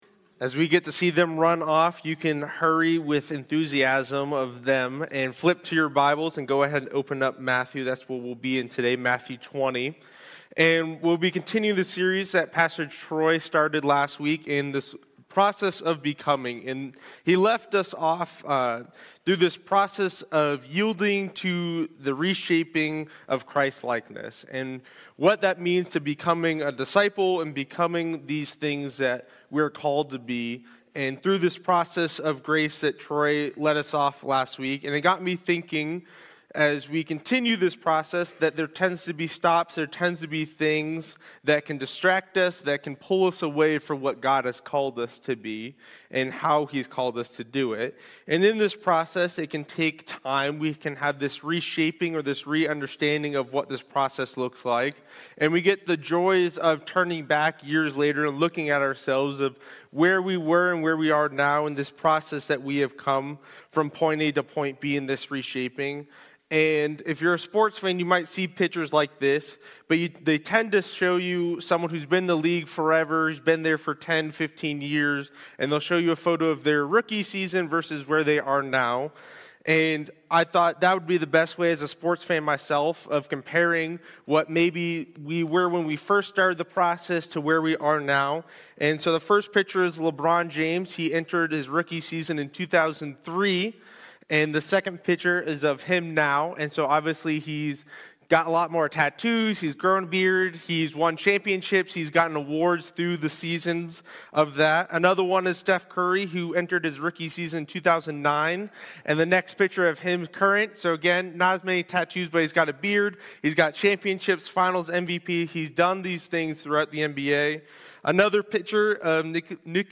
Sermons – Baker City Church of the Nazarene
For those who were not able to join us for our Sunday worship, we have posted the morning sermons so you can listen to them.